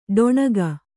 ♪ ḍoṇaga